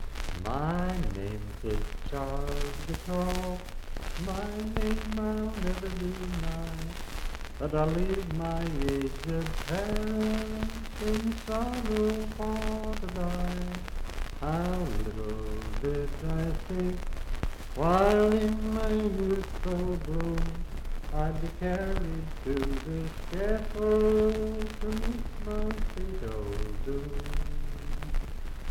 Unaccompanied vocal music
Verse-refrain 2(4).
Voice (sung)
Pocahontas County (W. Va.)